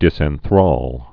(dĭsĕn-thrôl)